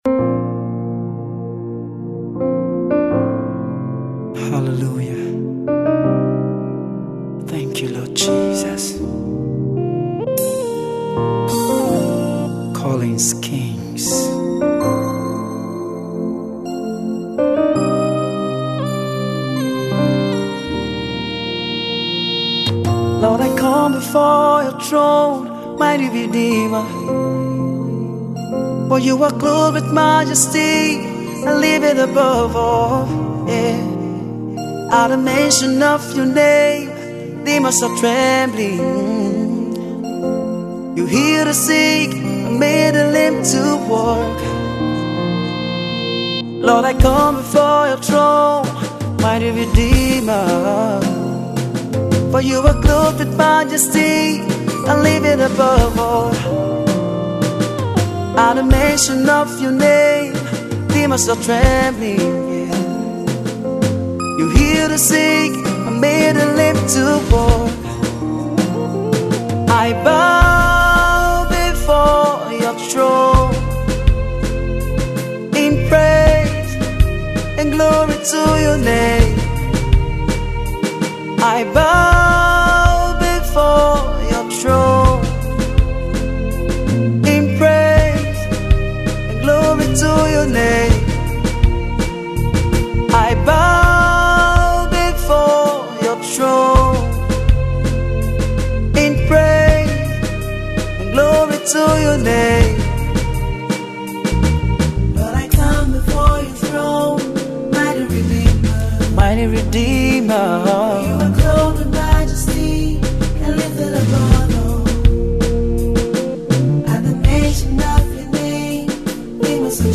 He is a gospel artist and song writer from OFM worldwide.